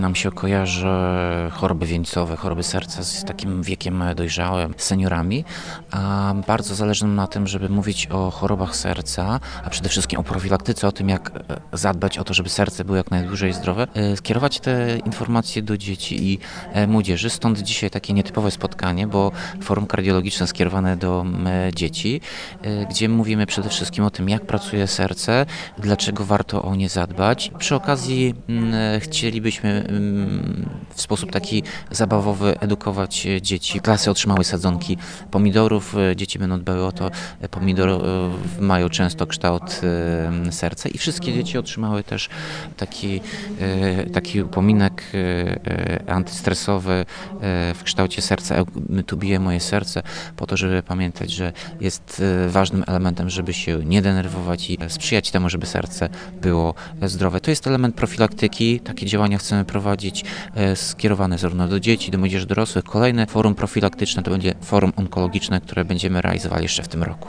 – Samorząd miejski stawia na profilaktykę zdrowotną organizując fora nie tylko kardiologiczne, ale również onkologiczne, szczepienia dla najmłodszych mieszkańców miasta, czy seniorów – mówi Tomasz Andrukiewicz, prezydent Ełku.